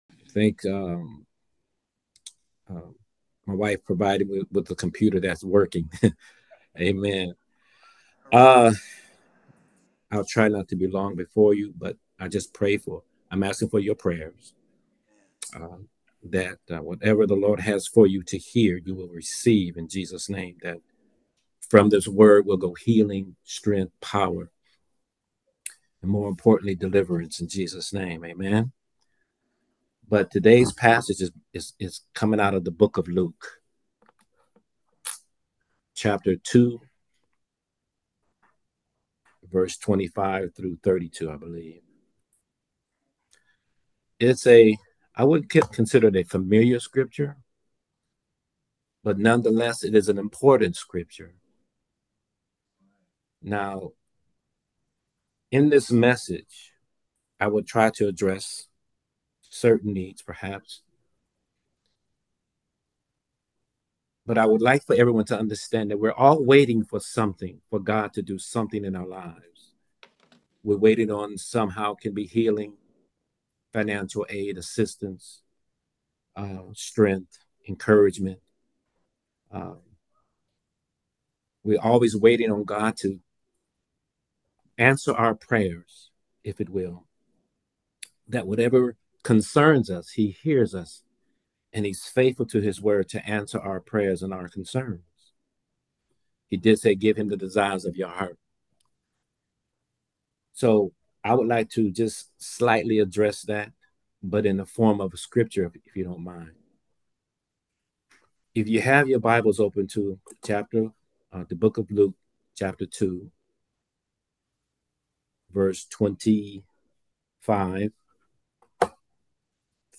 Simeon’s Example: The sermon focuses on Simeon, a devout priest who waited patiently for the promised Messiah.